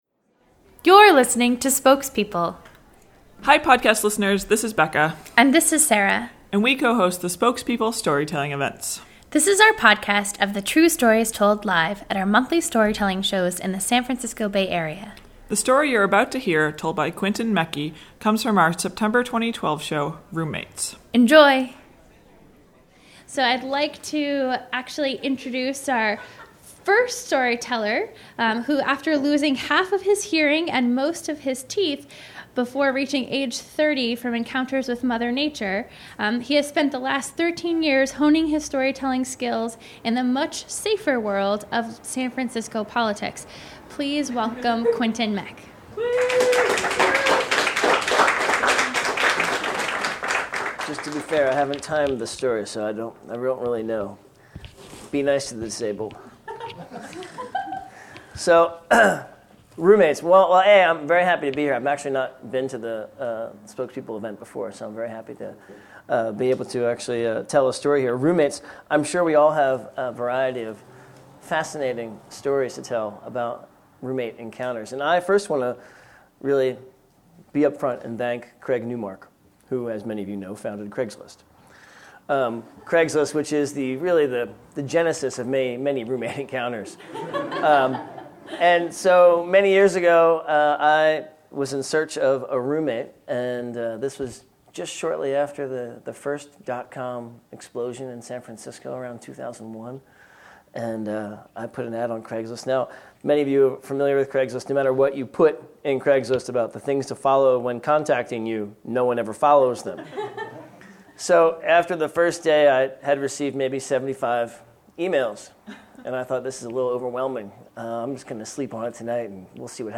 If it weren’t for him, where exactly would most of us get our crazy roommate stories? Thank you Craig for giving us many of us places to call home as well as our 21st podcast which was told live at the Red Poppy Art House for the September 2012 show, “Roommates.”